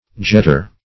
Search Result for " jetter" : The Collaborative International Dictionary of English v.0.48: Jetter \Jet"ter\ (j[e^]t"t[~e]r), n. One who struts; one who bears himself jauntily; a fop.